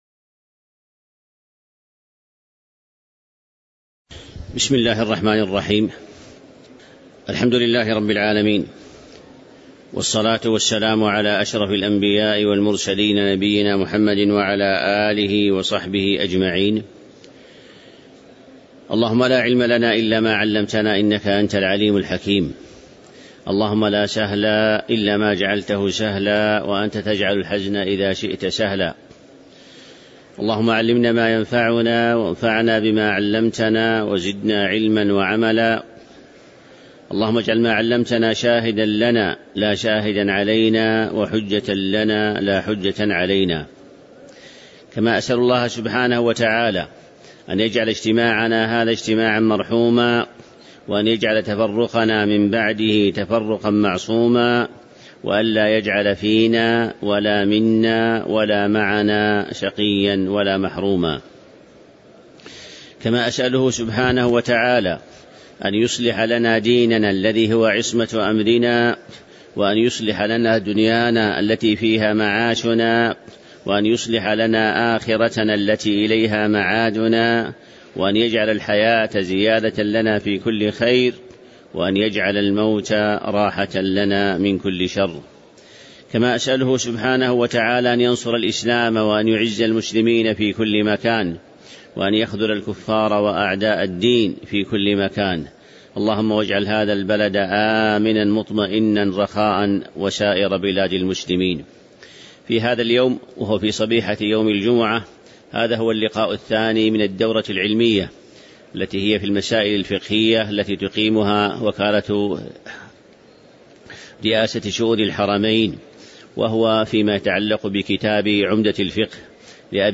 تاريخ النشر ٢٥ ذو القعدة ١٤٤٣ هـ المكان: المسجد النبوي الشيخ